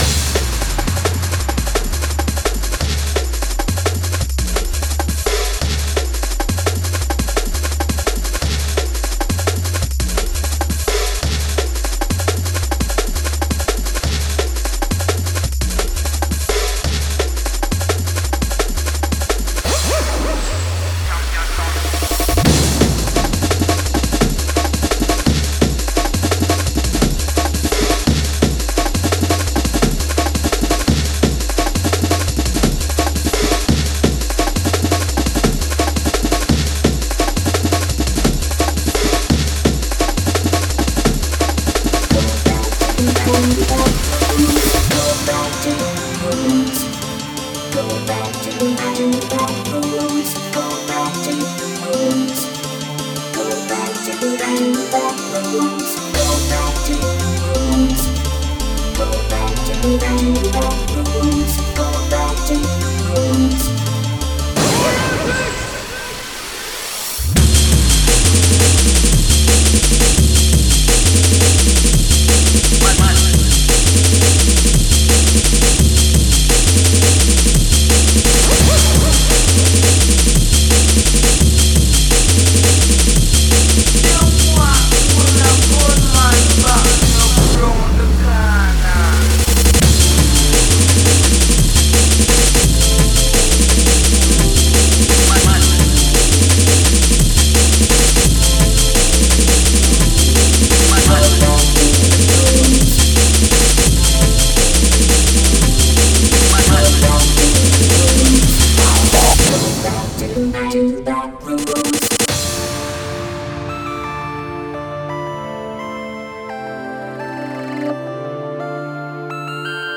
jungle oldschool
nice break drums !!!!